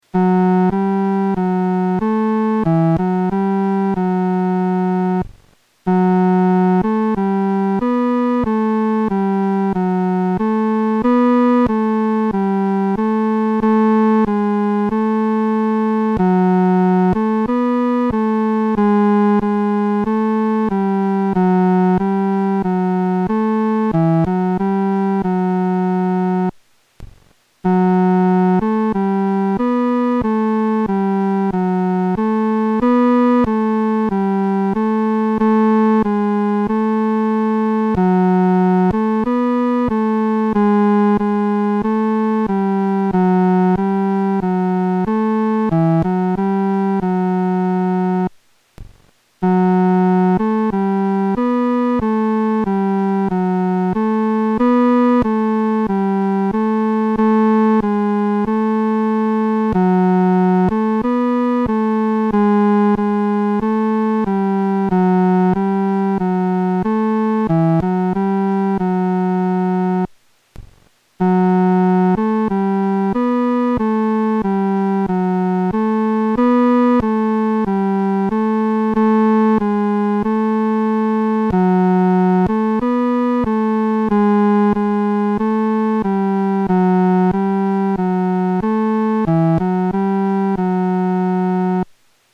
男高伴奏